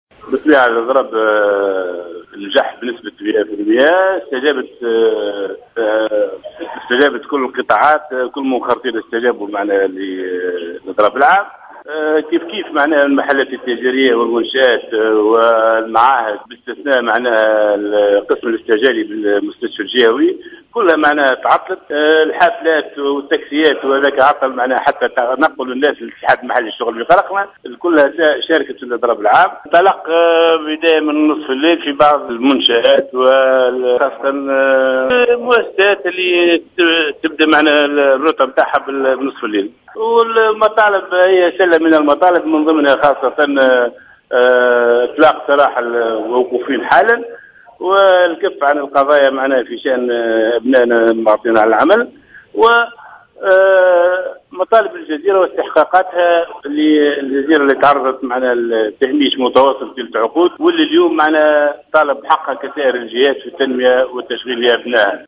تصريح للجوهرة "اف ام"